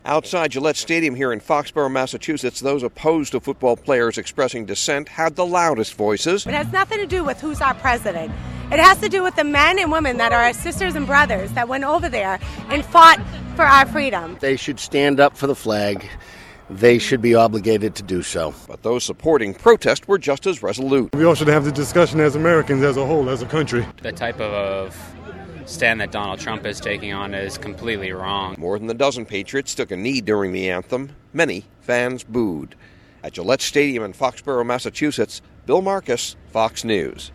(FOXBOROUGH, MASS) SEP 25 –  THERE WAS POLITICS TO GO ALONG WITH ALL THE BEER AT TAILGATE PARTIES OUTSIDE GILLETTE STADIUM IN FOXBOROUGH, MASSACHUSETTS SUNDAY.